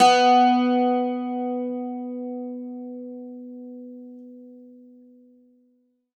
52-str05-bouz-b2.wav